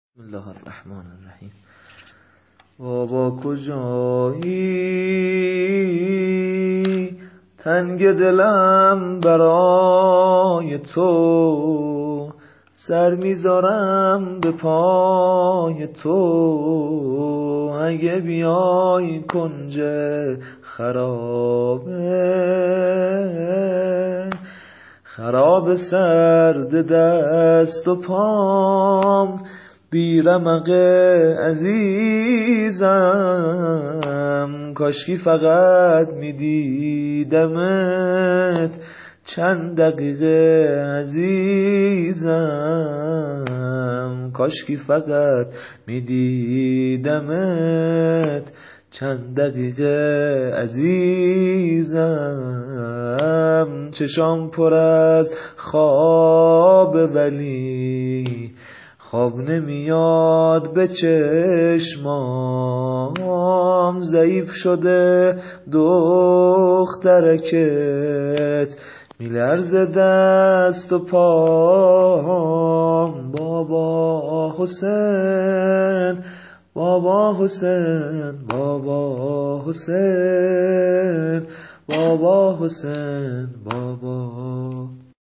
واحد شهادت حضرت رقیه(س) -( تهمت و حرف ناروا چه حرفایی شنیدم )